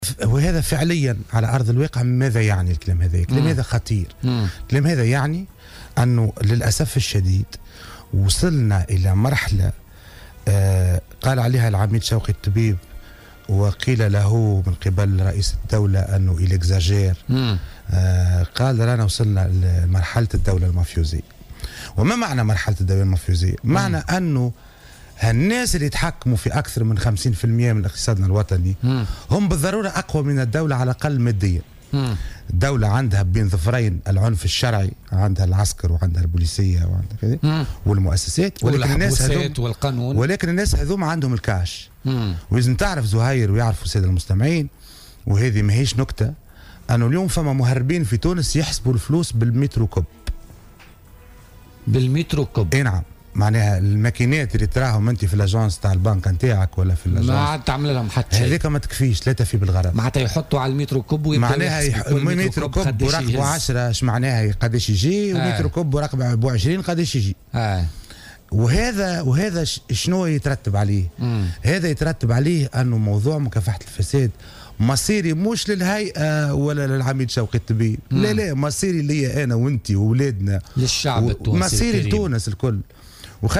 وأضاف بوعلاق، ضيف برنامج "بوليتيكا" اليوم الثلاثاء أن هناك الكثير منهم يستحوذون على أموال طائلة جراء عمليات التهريب والنشاط الموازي، مشيرا إلى أن موضوع مكافحة الفساد أصبح أمرا مصيريا.